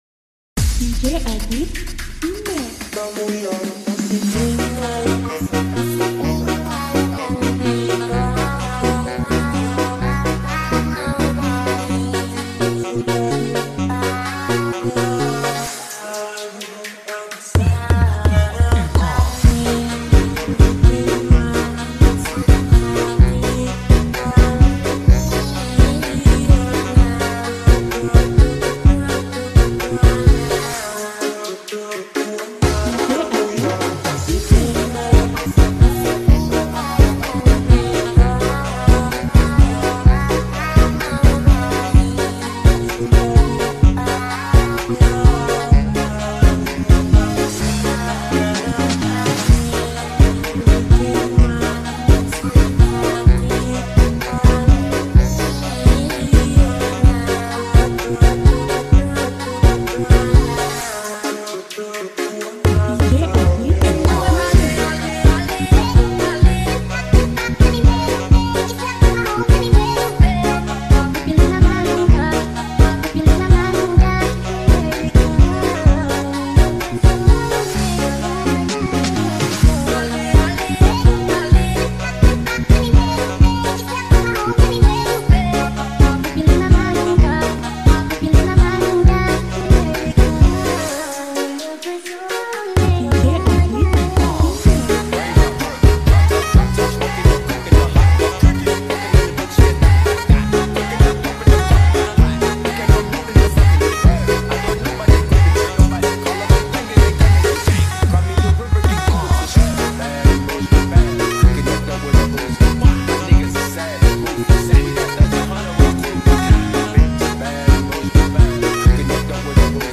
MELODI BBHC STYLE